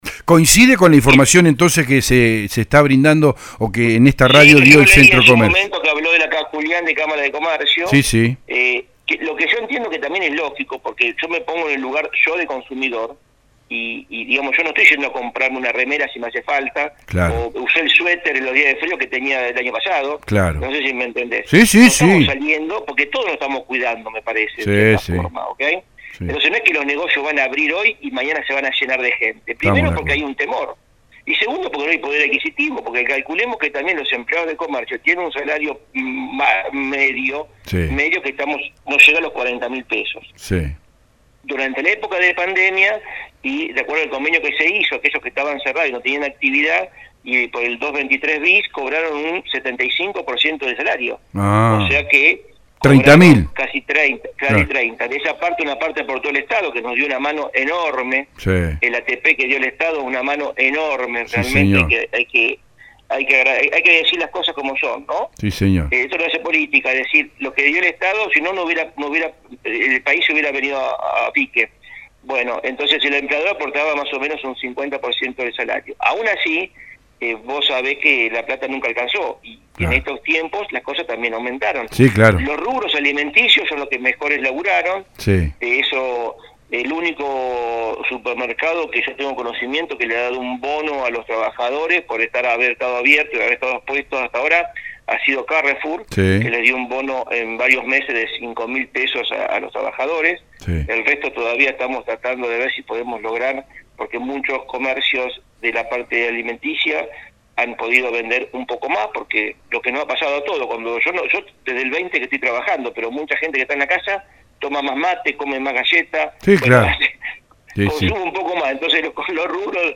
en el programa Con Zeta de radio EL DEBATE